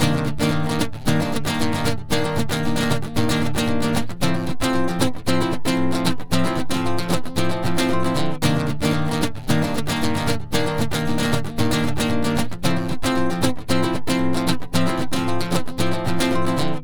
Ala Brzl 3 Nyln Gtrs-C.wav